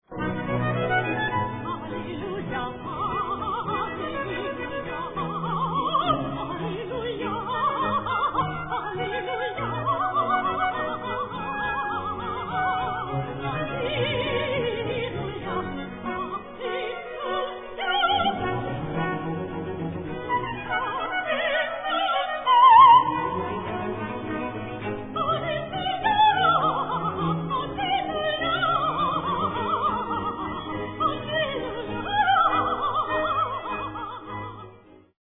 Three virtuosic soprano solo cantatas
Performed on period instruments.
5. Aria: